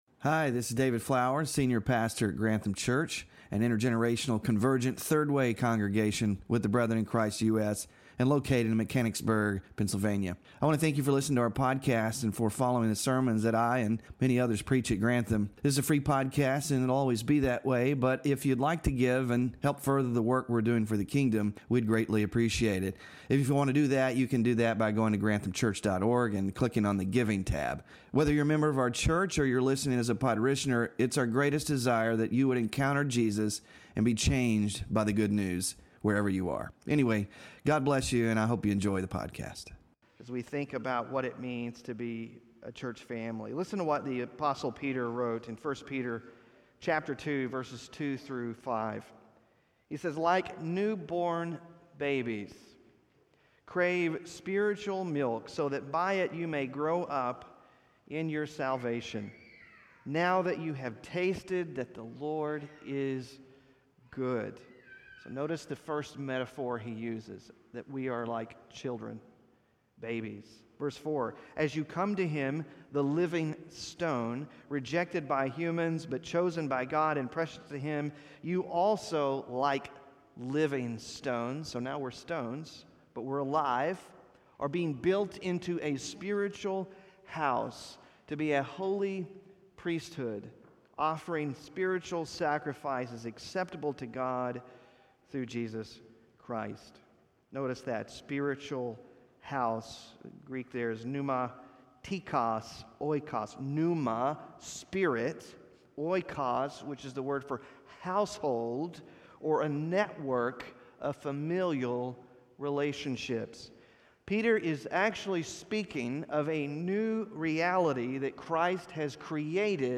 WORSHIP RESOURCES Sermon Slides (4-21-24) Small Group Discussion Questions